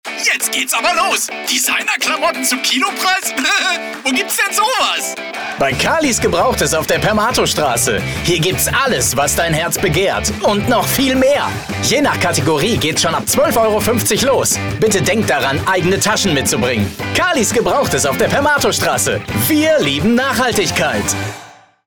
Male
Sprachproben